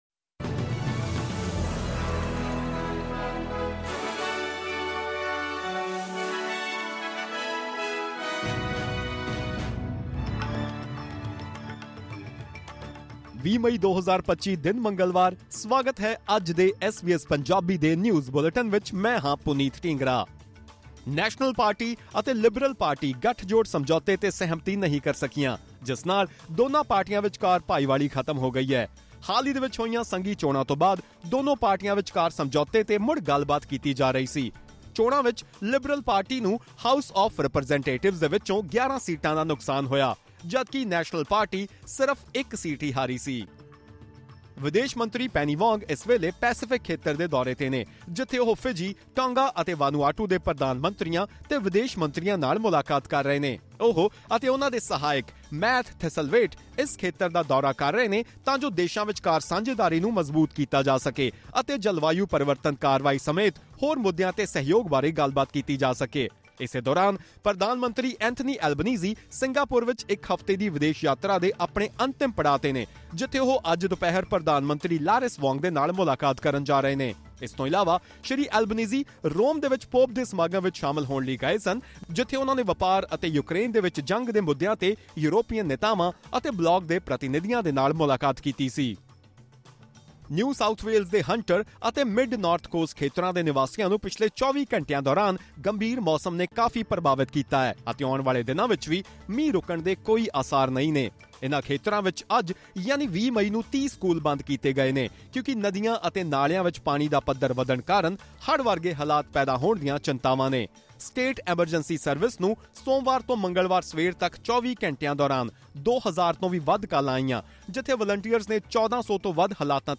Listen to the Top News of 20th May 2025 in Punjabi language.